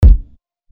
GUTTINGCATFISH KICK.wav